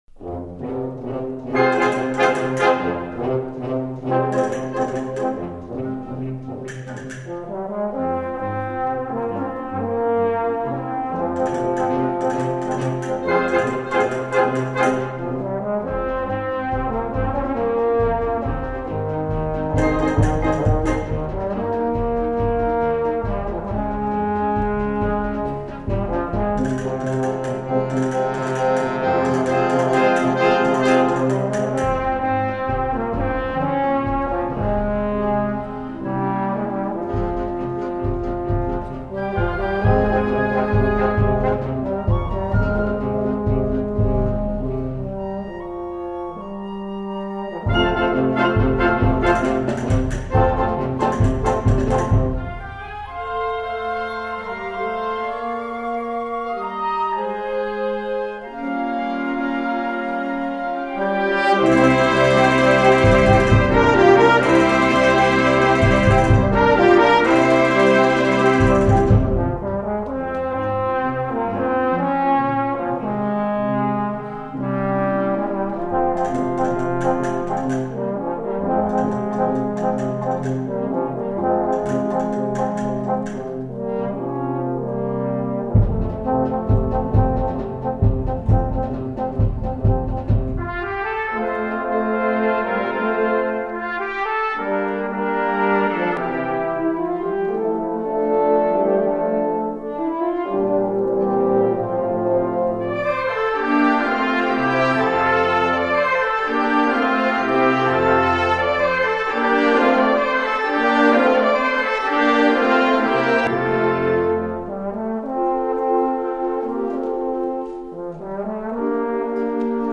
Voicing: Euphonium Solo w/ Band